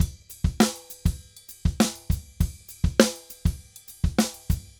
Drums_Salsa 100_4.wav